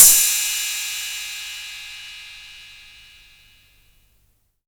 808CY_7_TapeSat.wav